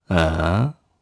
Ezekiel-Vox-Deny_jp_b.wav